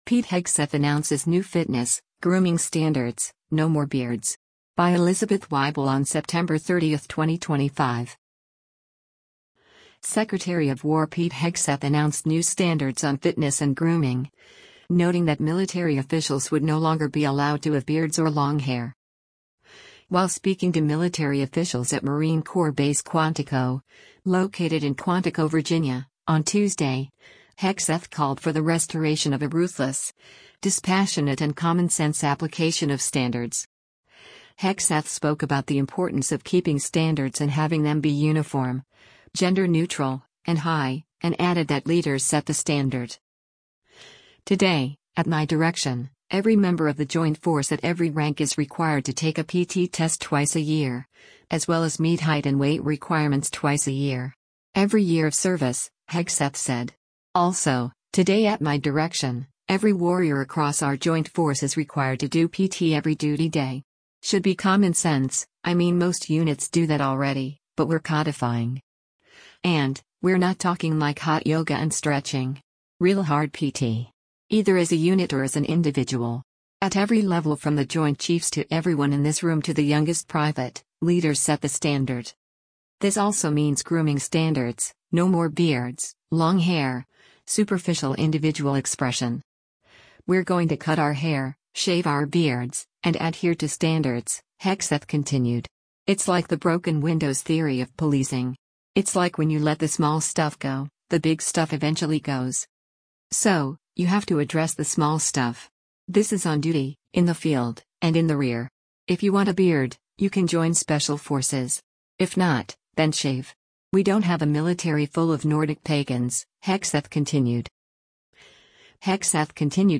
U.S. Secretary of War Pete Hegseth speaks to senior military leaders at Marine Corps Base